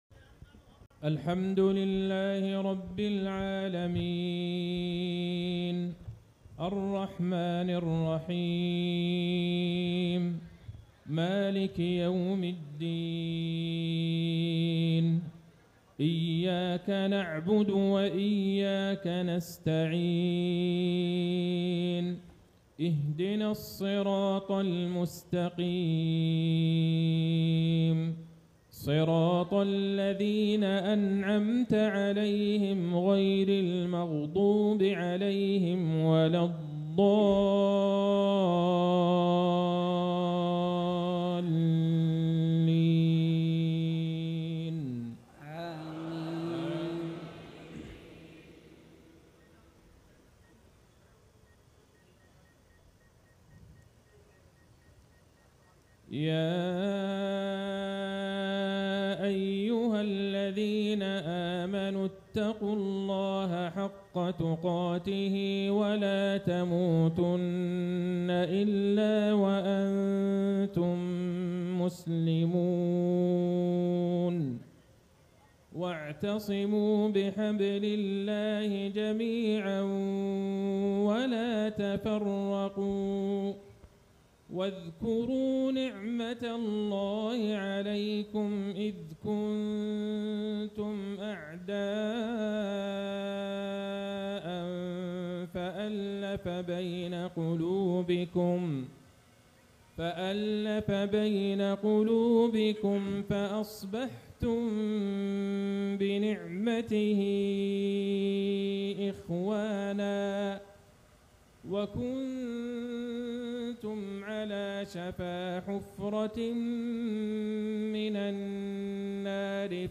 صلاة العشاء ٨-٥-١٤٤٦هـ في مؤتمر جمعية أهل الحديث في عاصمة الهند نيودلهي > تلاوات و جهود الشيخ عبدالله البعيجان > تلاوات وجهود أئمة الحرم النبوي خارج الحرم > المزيد - تلاوات الحرمين